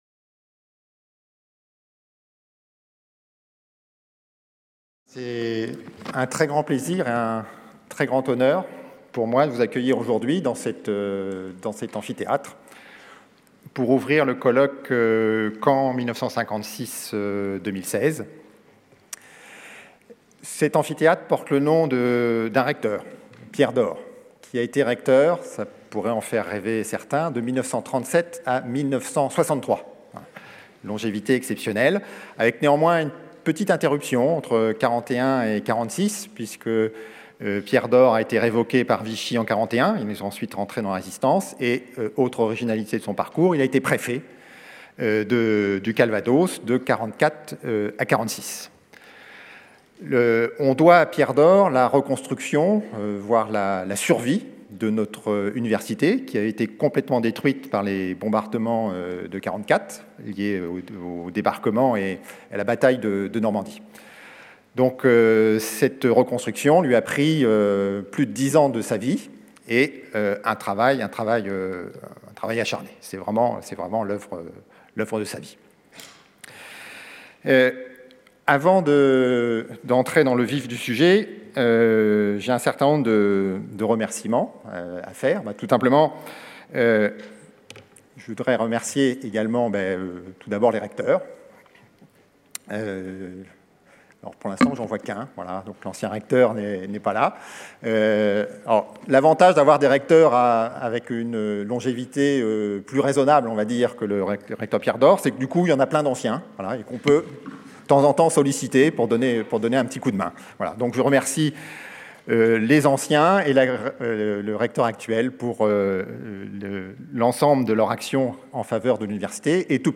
La Recherche. Construire demain. Allocution d'ouverture | Canal U
C’est le sens de la journée nationale qui s'est tenue à Caen le 3 novembre 2016 - journée qui s'est conclue par une allocution du président de la République.